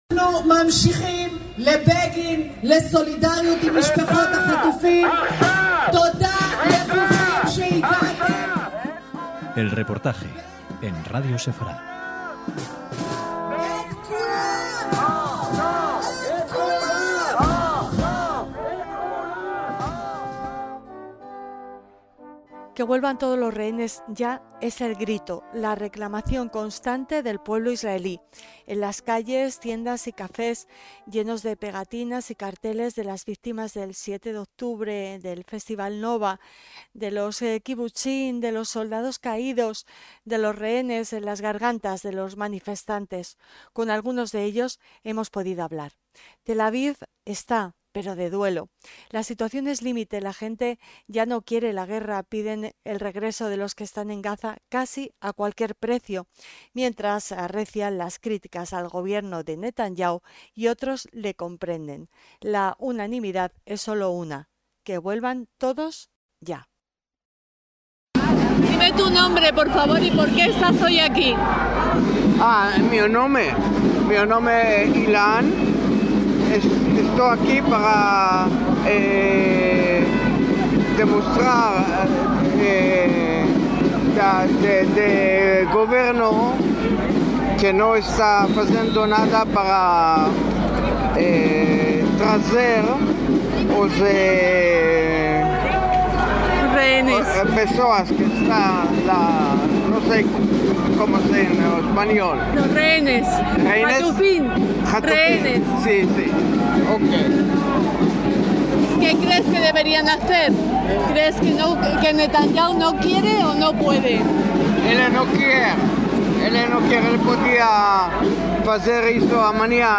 EL REPORTAJE - Qué vuelvan todos los rehenes ya es el grito, la reclamación constante del pueblo israelí.
Con algunos de ellos hemos podido hablar.